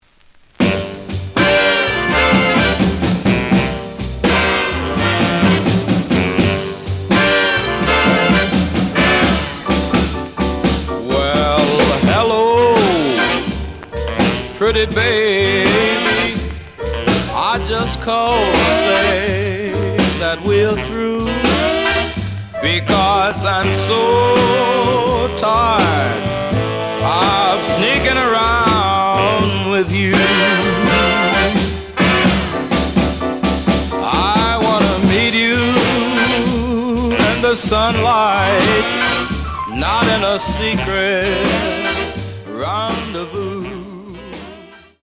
The audio sample is recorded from the actual item.
●Genre: Blues
Slight edge warp.